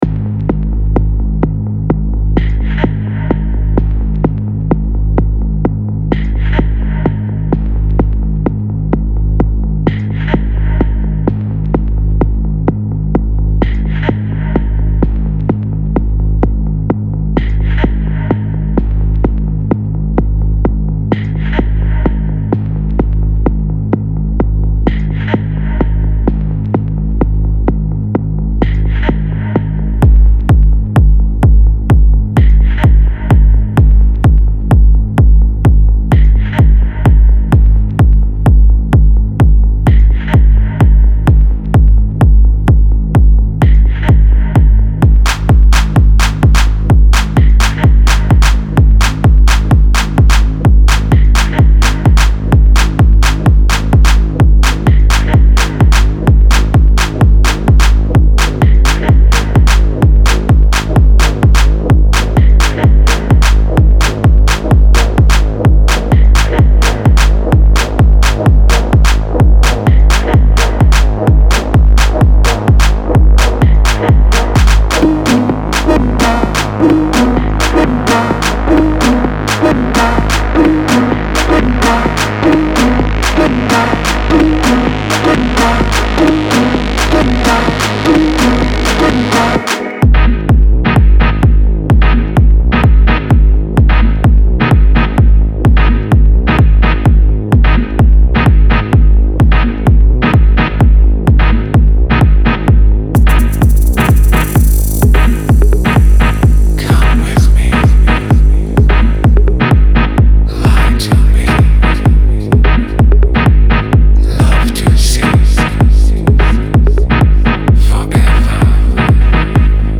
Что то похожее на техно.